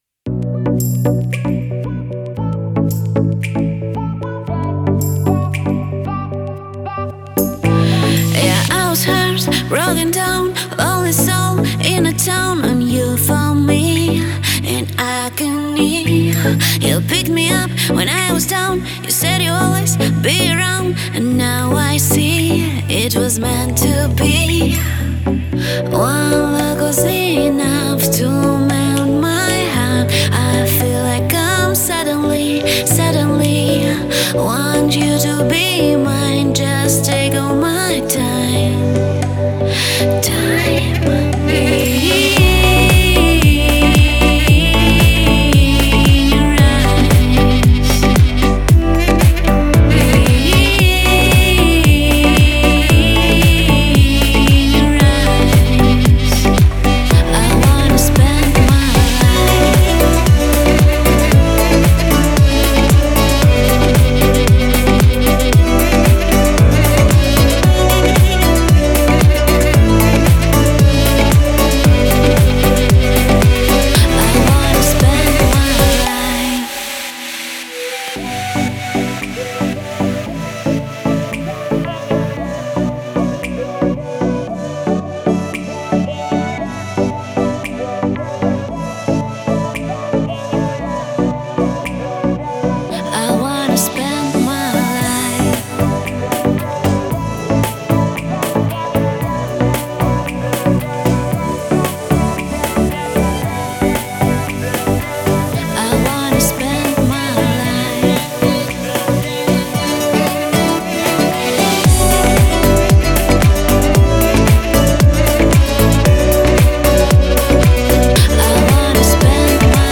это зажигательный трек в жанре EDM
а ритмичные биты создают атмосферу веселья и свободы.